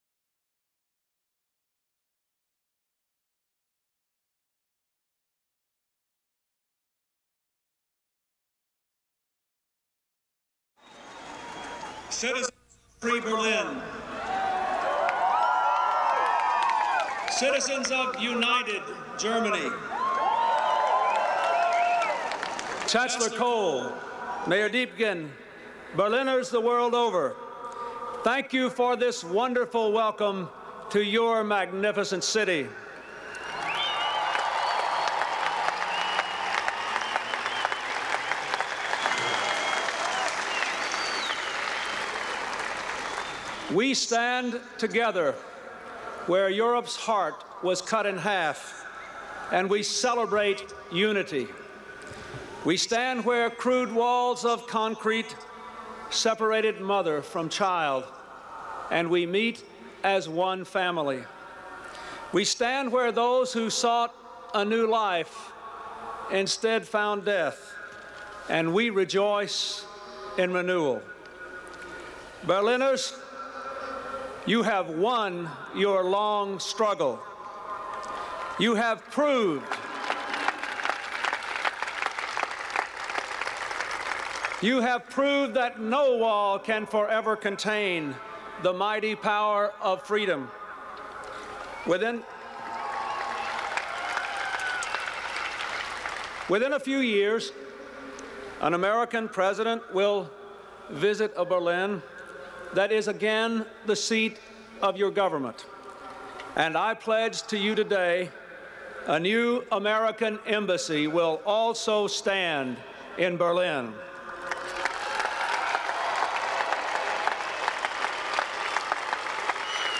Presidential Speeches
July 12, 1994: Remarks at the Brandenburg Gate